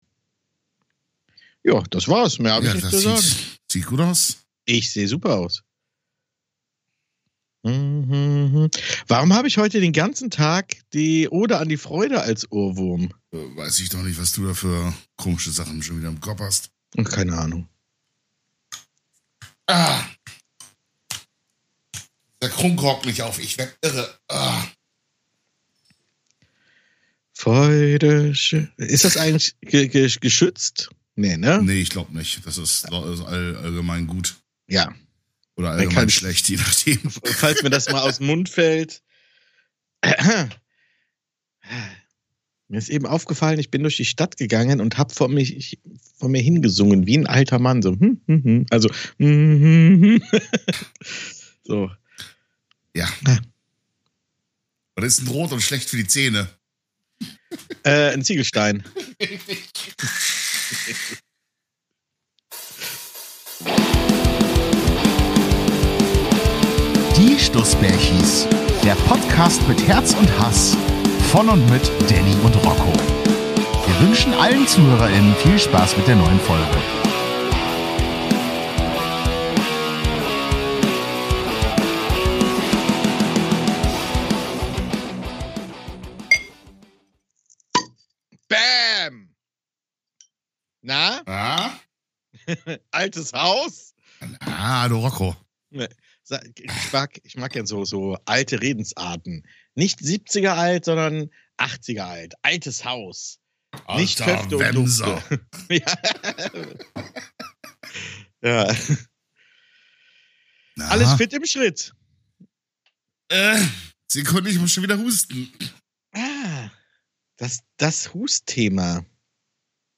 Ein Störgeräusch